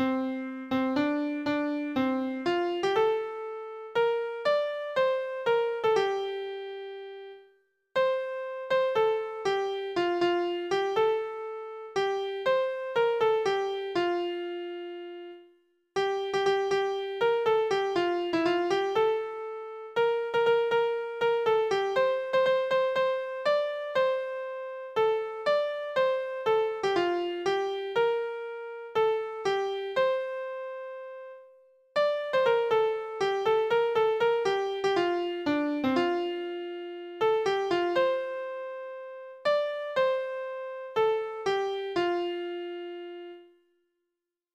メロディーのみ